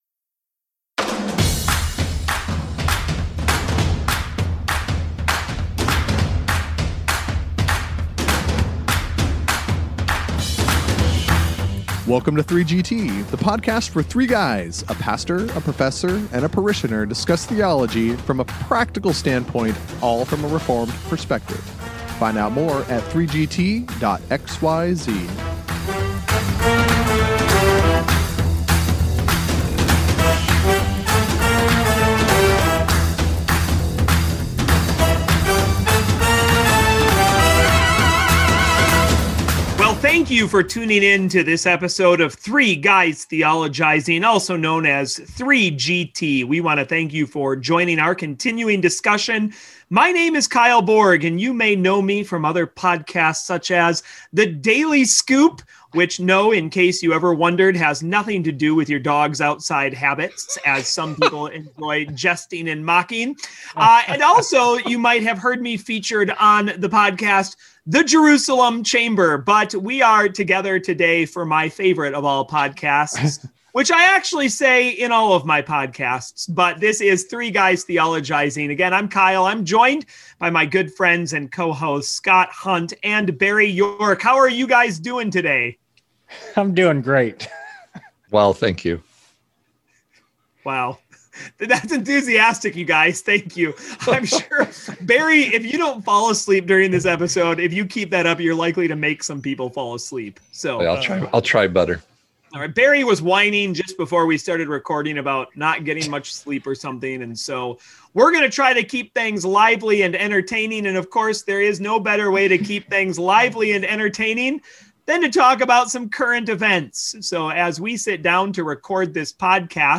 Join the robust conversation on this episode on Three Guys Theologizing!